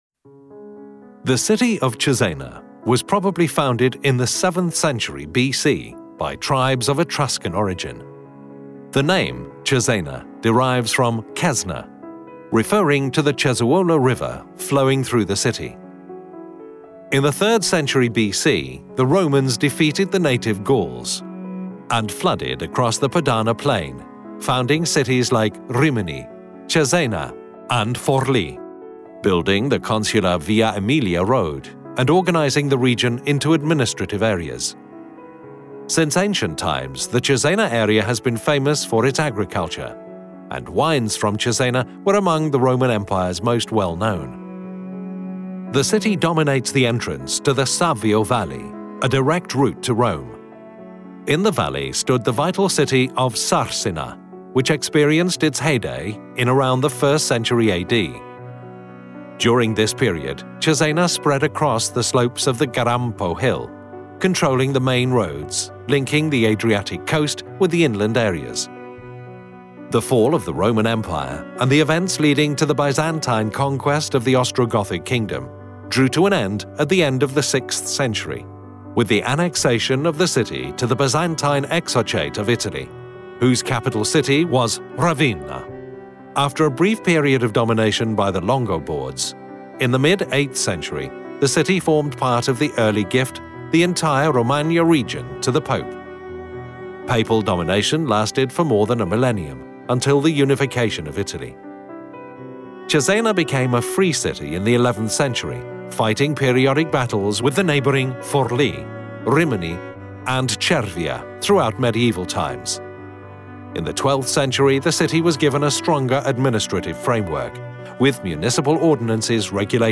Audioguida Storia di Cesena